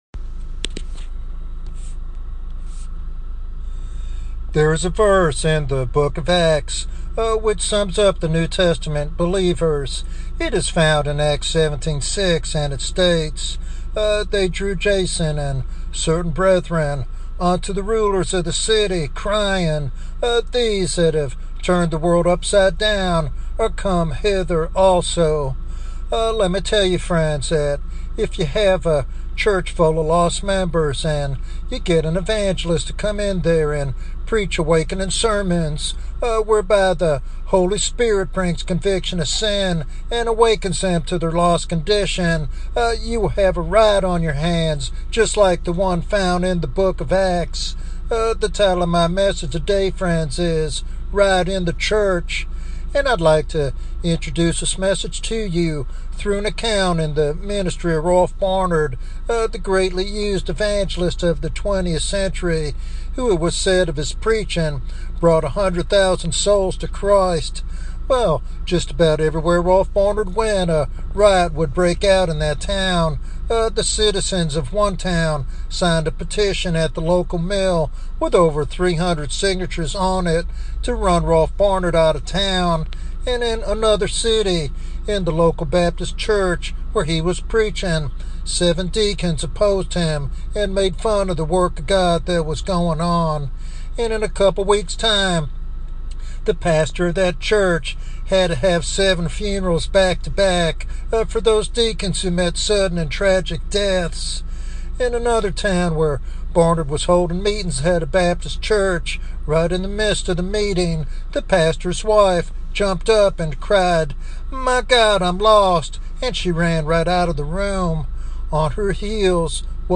This sermon serves as a passionate call to awaken both pastors and congregations to the urgency of true salvation.